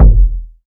KICK.114.NEPT.wav